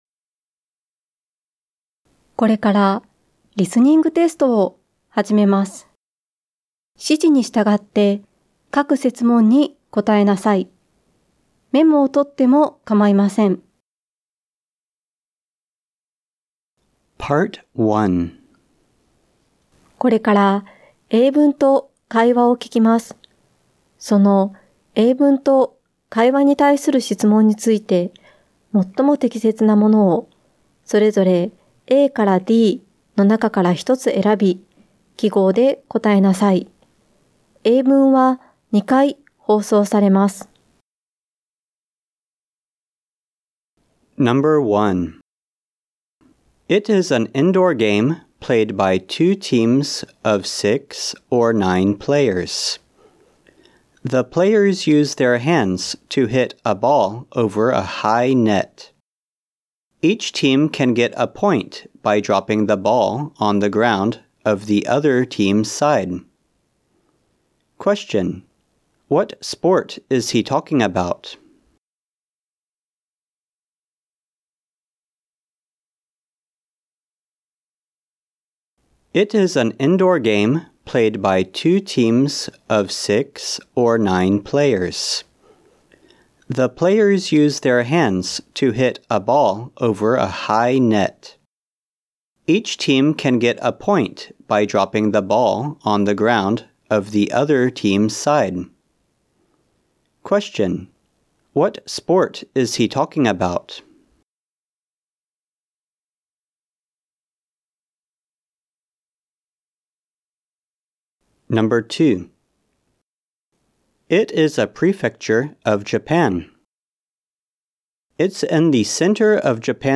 英語リスニング問題（高等学校） | プール学院中学校・高等学校